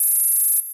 forcefield2.wav